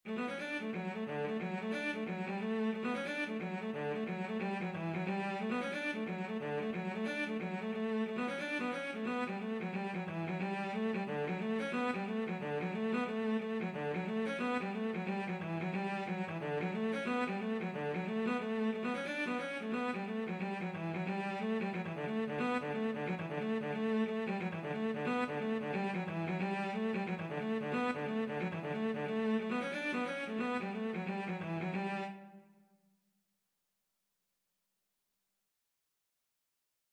Free Sheet music for Cello
4/4 (View more 4/4 Music)
D major (Sounding Pitch) (View more D major Music for Cello )
Easy Level: Recommended for Beginners with some playing experience
Cello  (View more Easy Cello Music)
Traditional (View more Traditional Cello Music)